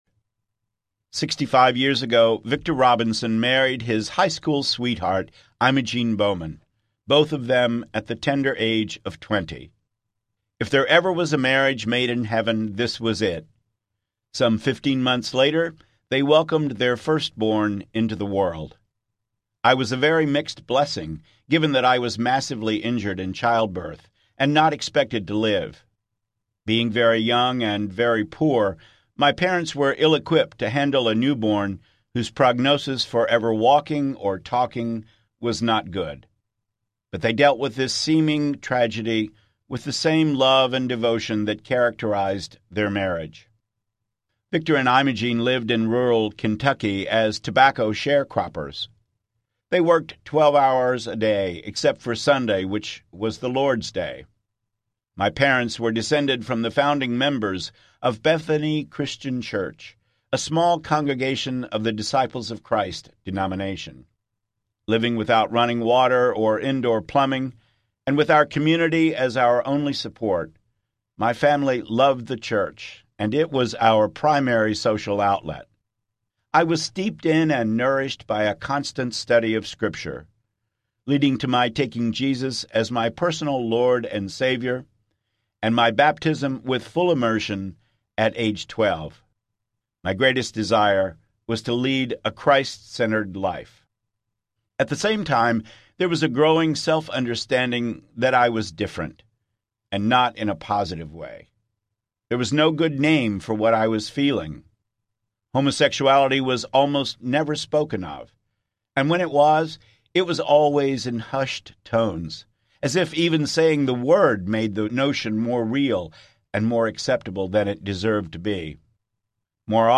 God Believes in Love Audiobook